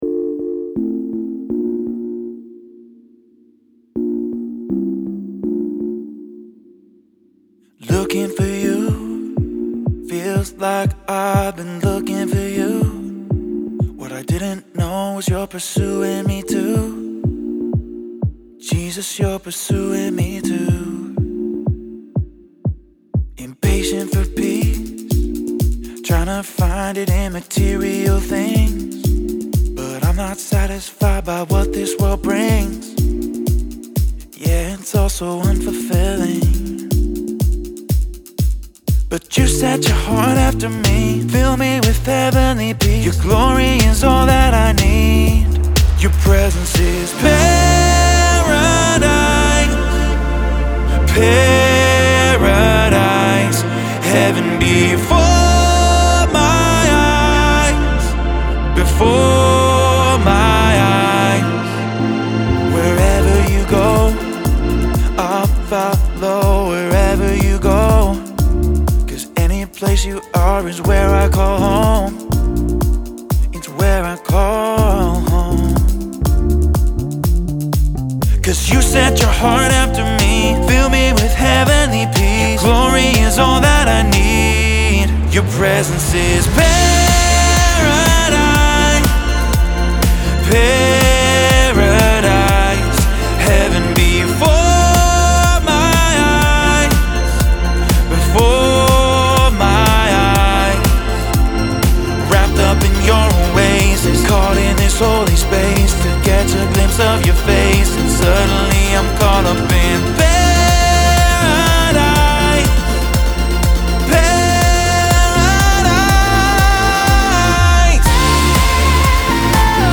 Pop Songs w/ Vocals
Vocal / Pop / Electronic